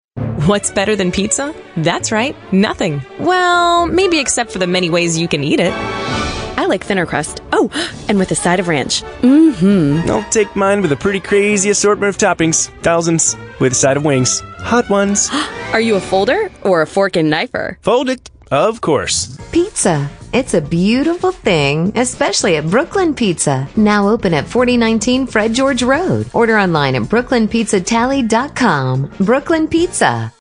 Radio Spot 3